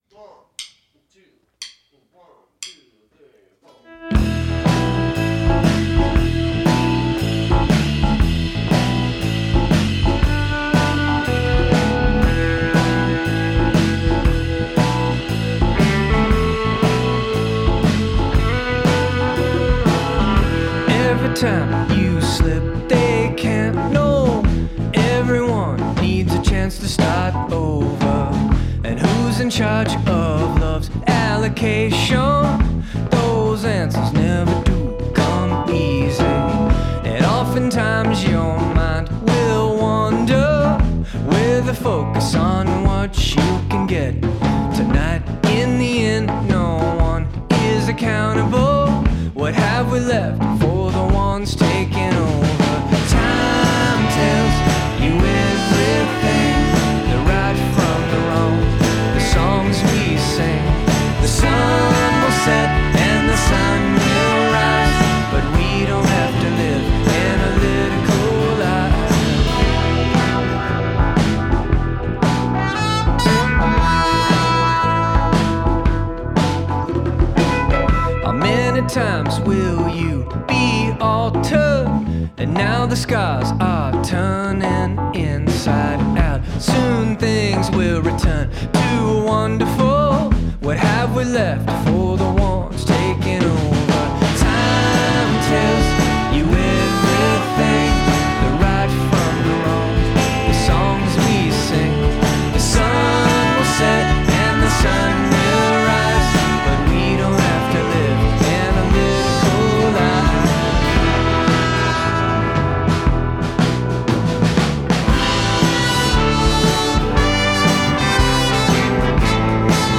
organ groove
all anchored by a easy going vocal and great melody